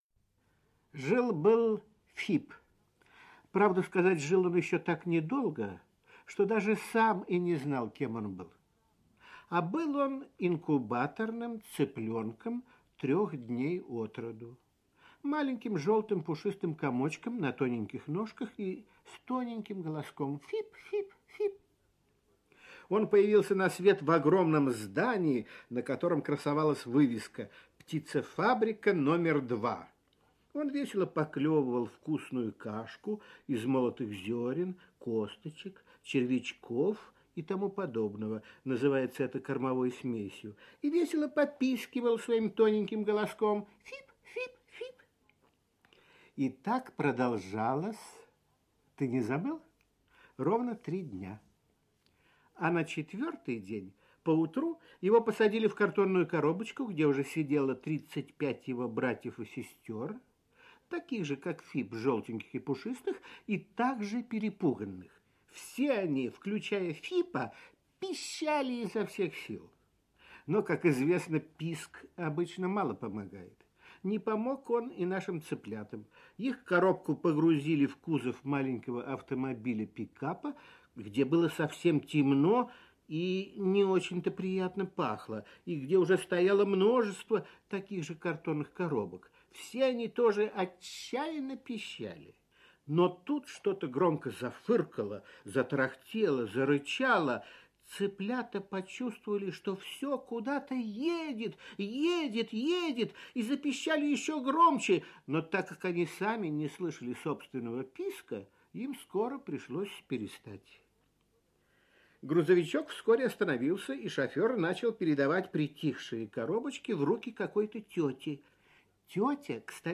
Жил-был Фип - аудиосказка Заходера Б. Сказка про инкубаторного цыпленка Фипа.